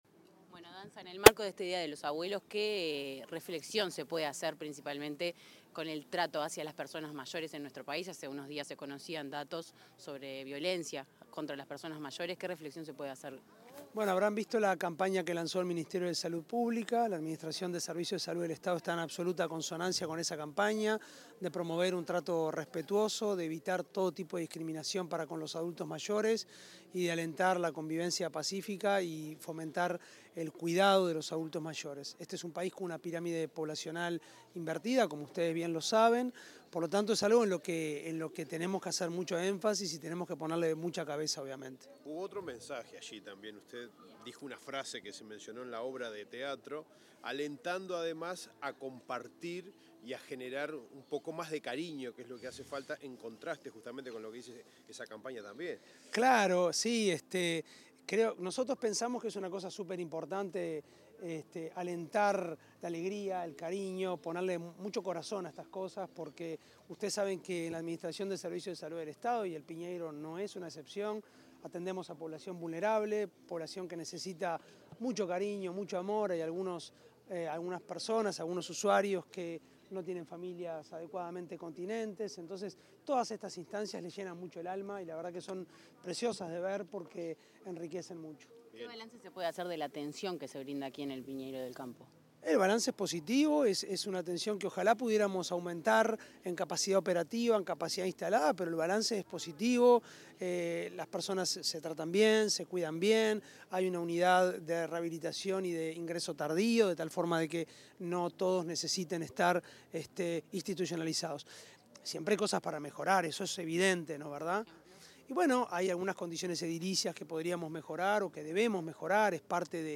Declaraciones del presidente de ASSE, Álvaro Danza
El presidente de la Administración de los Servicios de Salud del Estado (ASSE), Álvaro Danza, dialogó con la prensa al finalizar la actividad